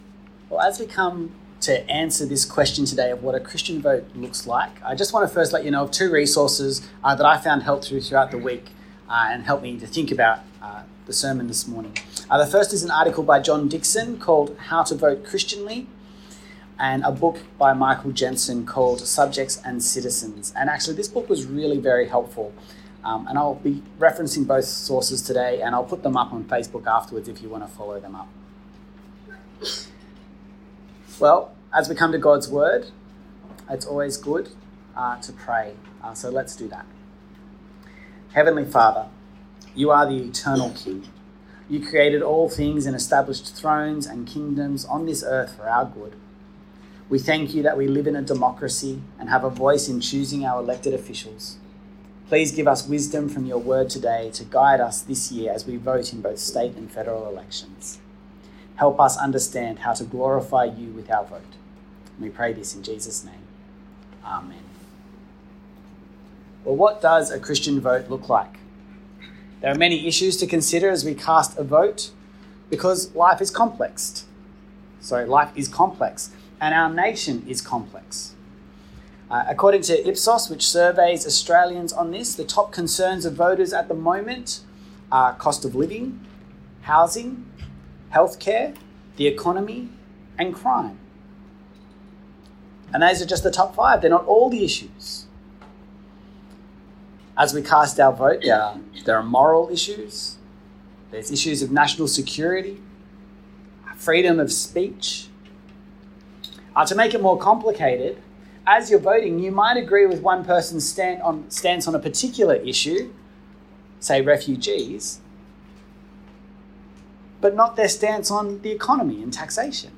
From Series: "One Off Sermons"